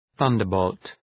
Προφορά
{‘ɵʌndər,bəʋlt} (Ουσιαστικό) ● κεραυνός